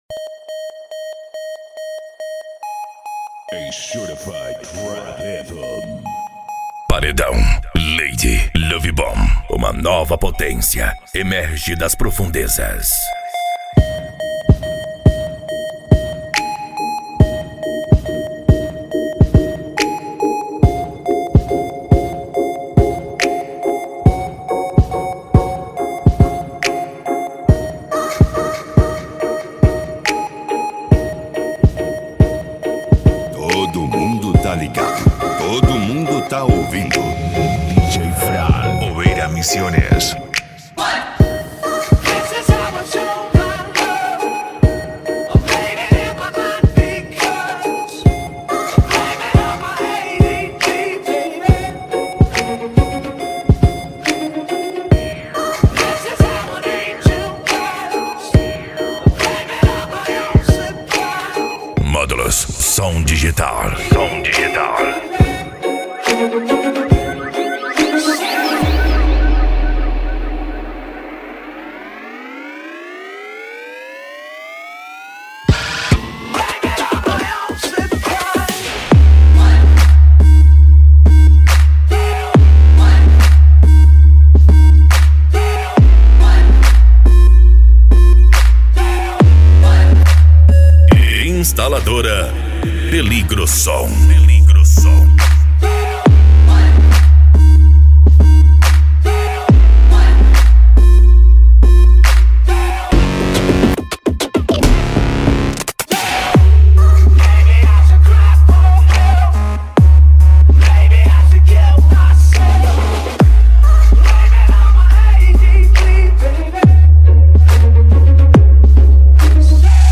Bass
Deep House
Eletronica
Psy Trance